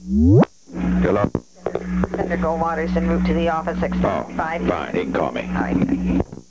Location: White House Telephone
The White House operator talked with the President.